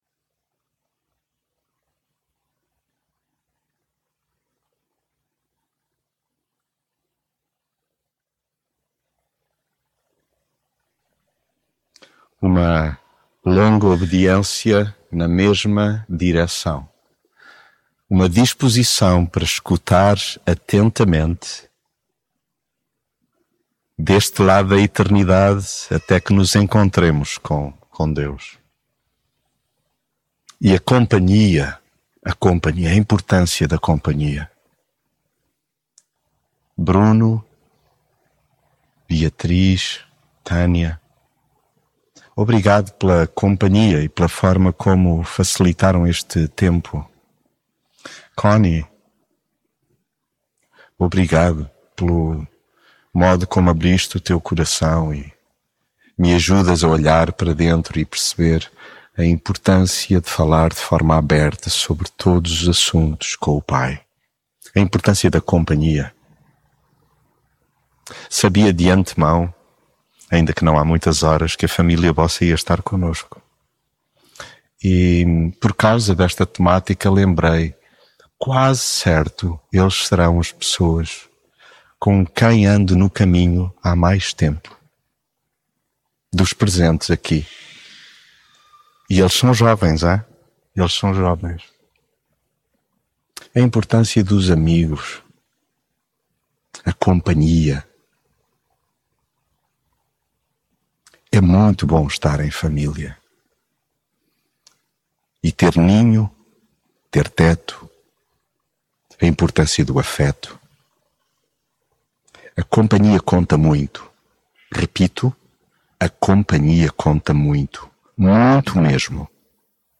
mensagem bíblica A companhia conta muito…